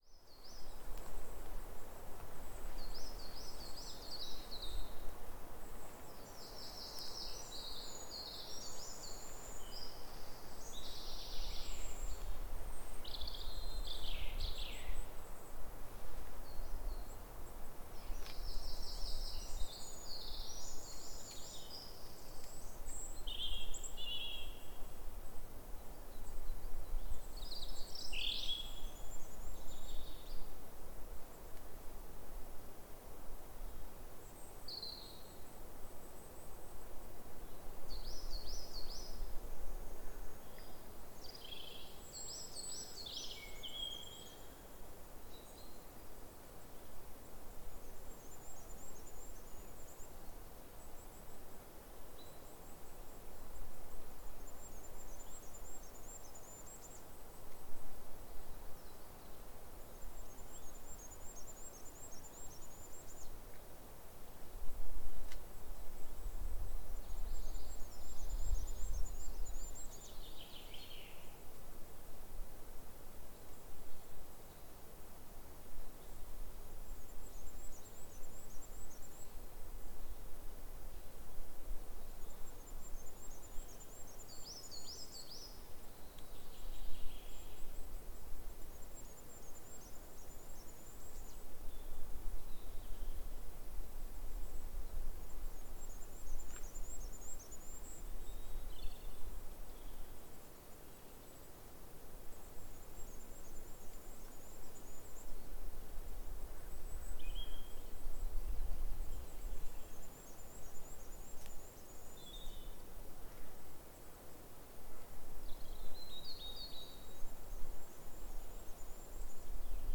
In Fernworthy Forest, on Dartmoor — ambience in a stand of Sitka spruce (1) Sound Effect — Free Download | Funny Sound Effects